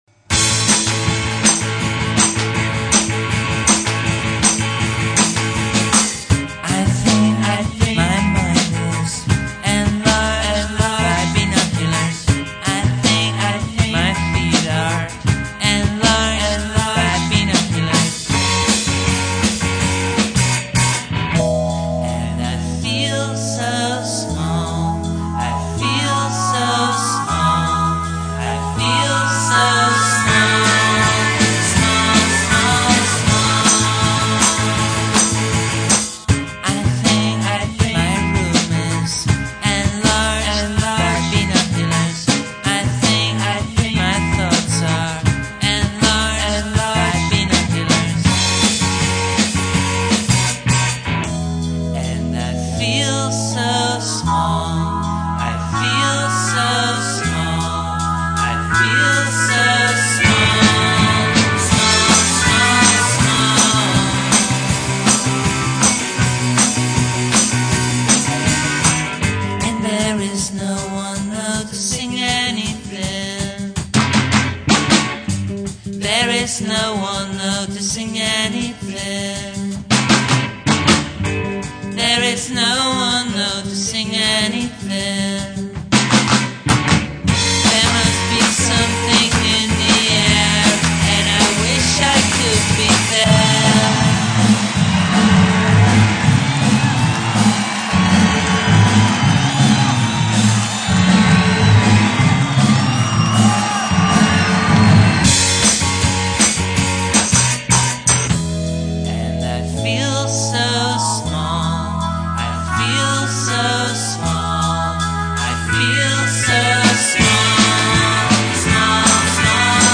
where : Studio Aluna , Amsterdam